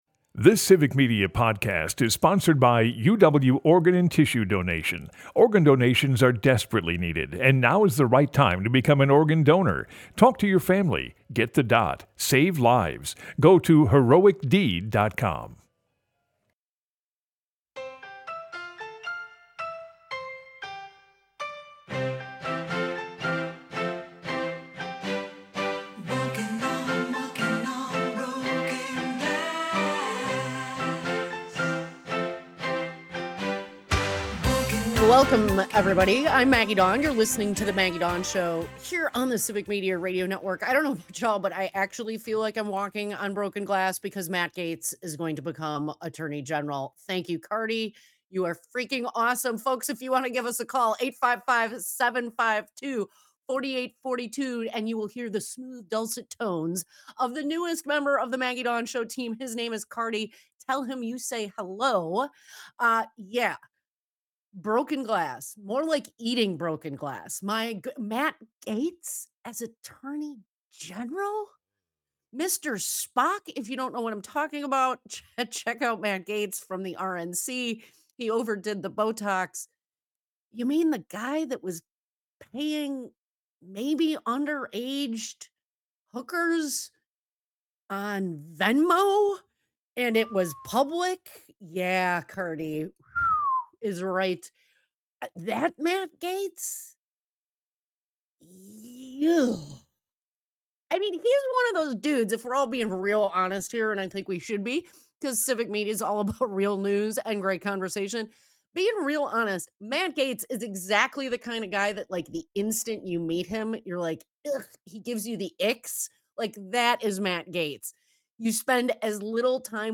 Broadcasts live, 2 - 4 p.m. across Wisconsin.
We get callers from both parties giving us their thoughts on who should greet that list.